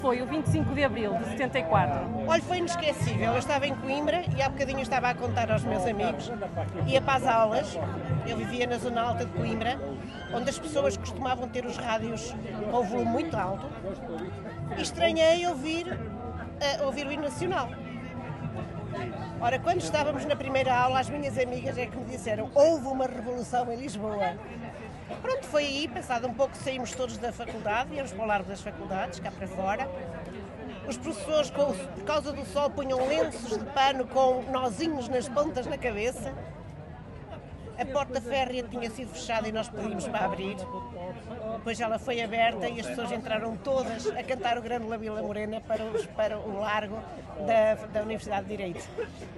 Terminada a Assembleia Municipal extraordinária, que assinalou o 25 de Abril, encontrámos, numa esplanada ali perto, um grupo de amigos que nos contou como foi vivido aquele dia.